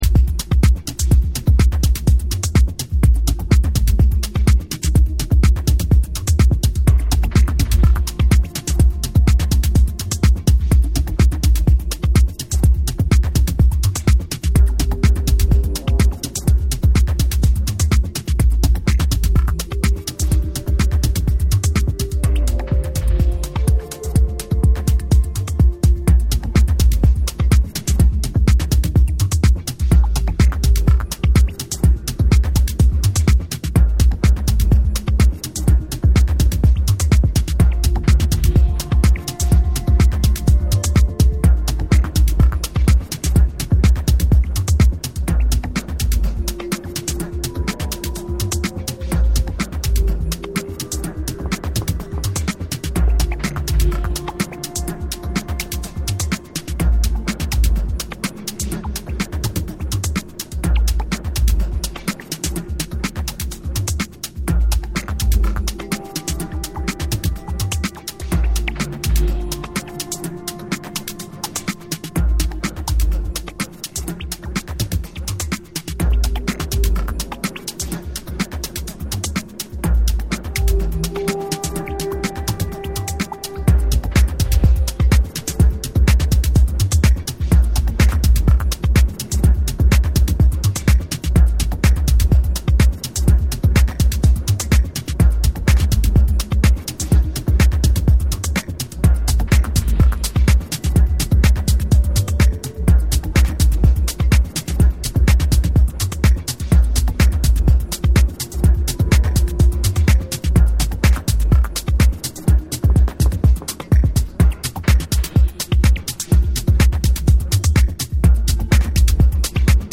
The EP includes two trippy and straight minimal tracks.